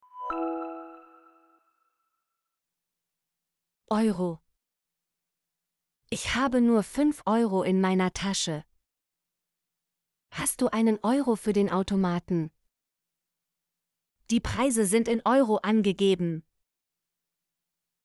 euro - Example Sentences & Pronunciation, German Frequency List